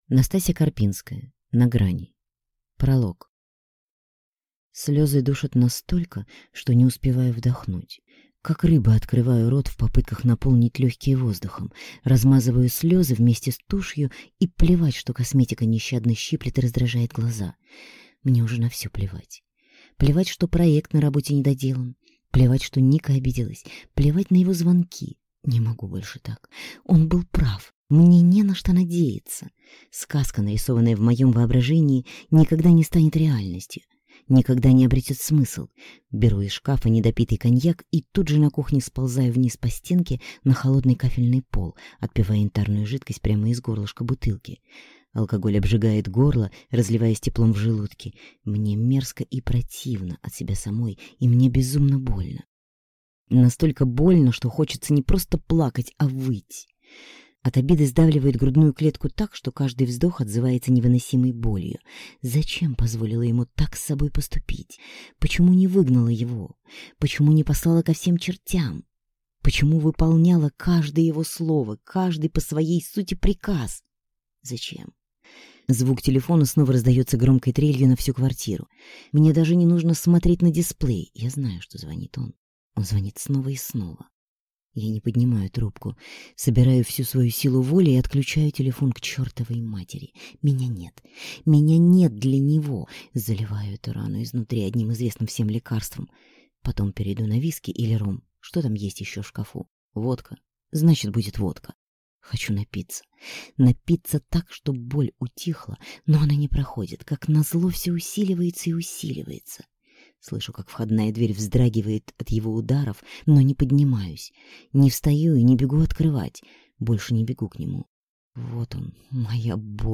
Аудиокнига На грани | Библиотека аудиокниг